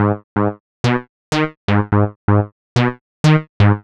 cch_synth_jupiter_125_E.wav